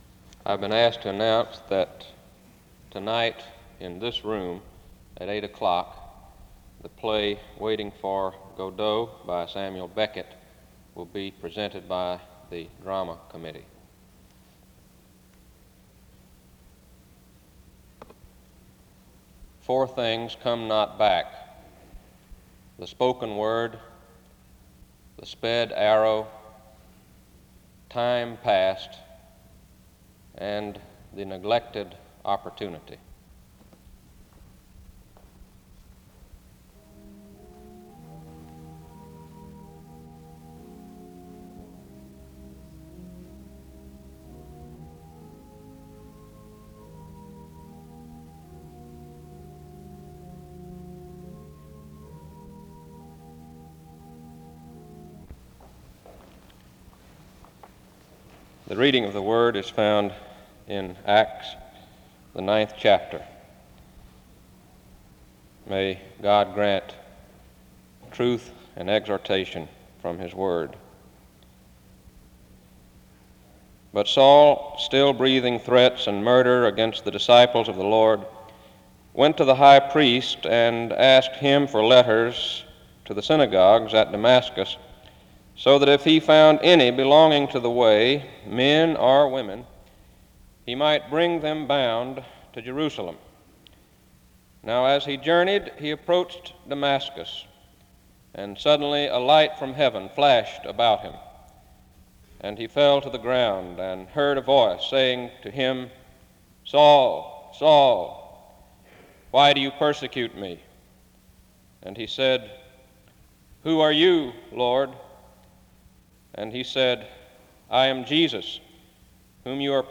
The service begins with opening announcements and music from 0:00-0:55. Acts 9:1-9 is read from 0:58-2:36.
SEBTS Chapel and Special Event Recordings SEBTS Chapel and Special Event Recordings